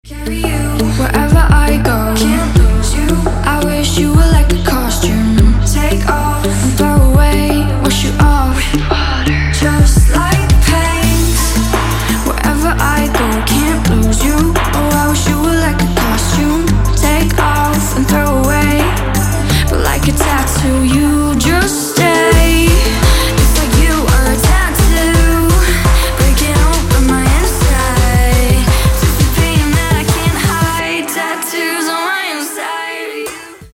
женский вокал
dance
EDM
club
vocal